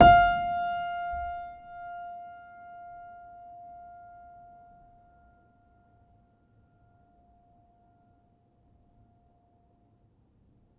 healing-soundscapes/Sound Banks/HSS_OP_Pack/Upright Piano/Player_dyn3_rr1_028.wav at main